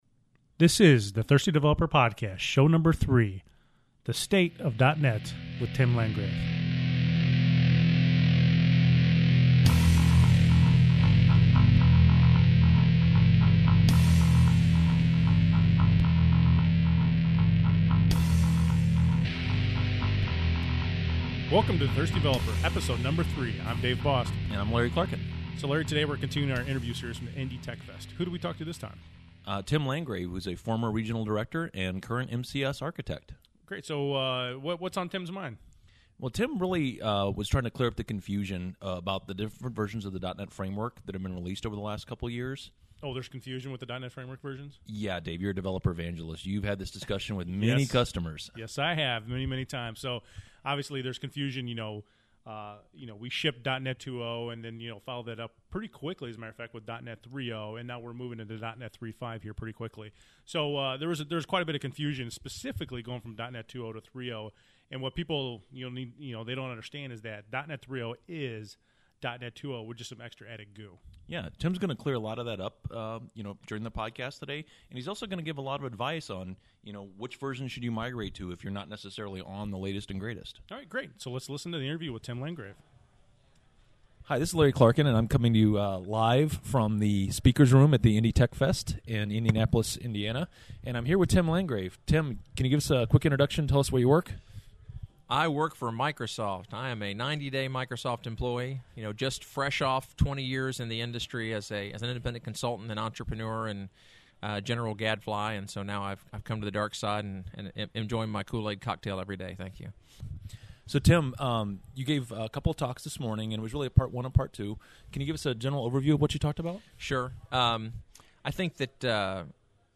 This is the second in our series of interviews from the IndyTechfest in Indianapolis, IN.